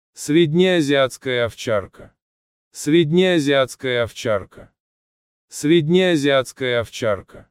Say it in Russian: